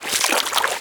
water2.wav